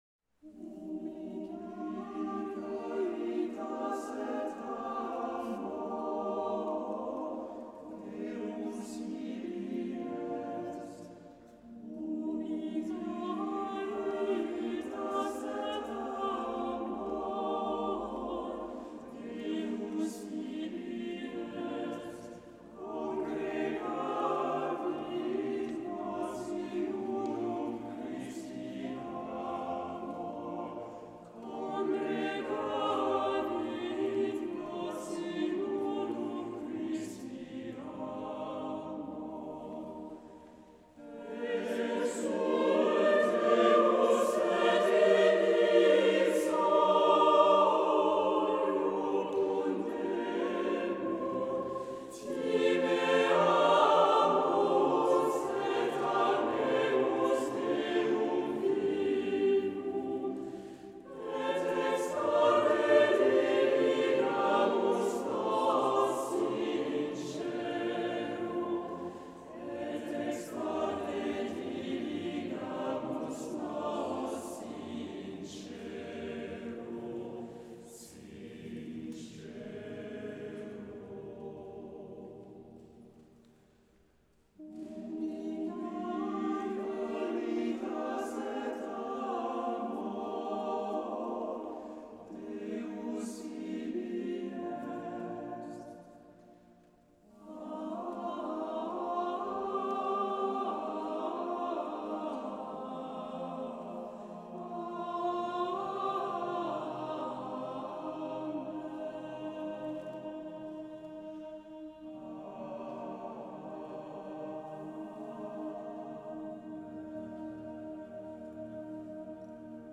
Retrouvez ici des extraits « live » de nos concerts !